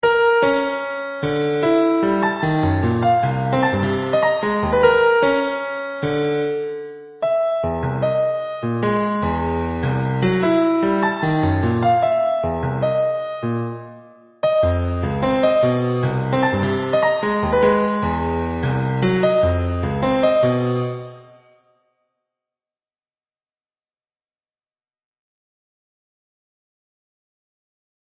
Les mesures répétées créent chaque fois une relative cohérence du morceau, mais c'est tout de même pour les petites tailles n que cela fonctionne le mieux.